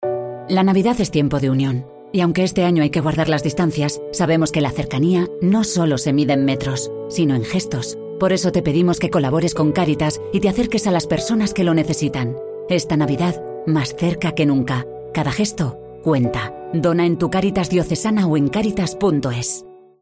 Cuña Cáritas